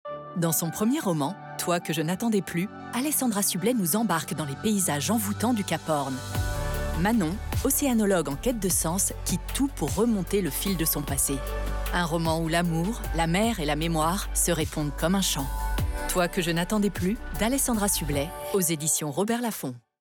Pub TV / Web Alessandra Sublet voix amicale voix chaleureuse voix mystérieuse Voix amicale Catégories / Types de Voix Extrait : Votre navigateur ne gère pas l'élément video .